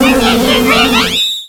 Cri d'Apireine dans Pokémon X et Y.